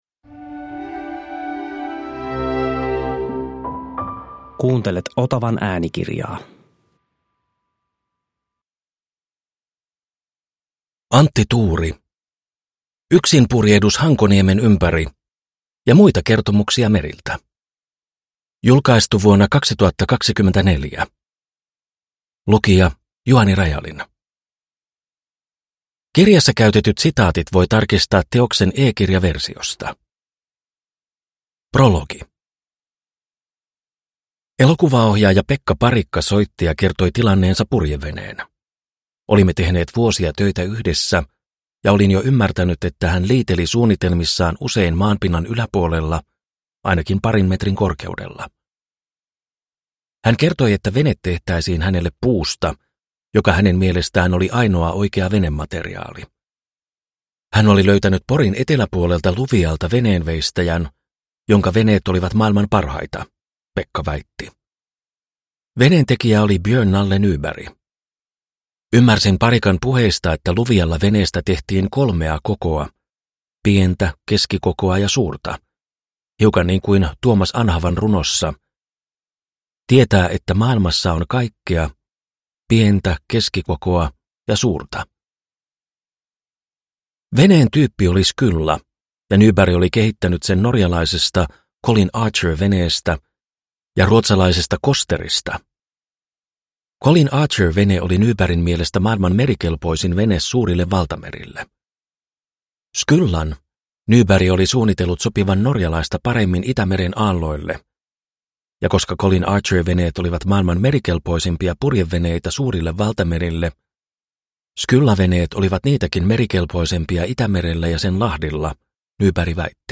Yksinpurjehdus Hankoniemen ympäri – Ljudbok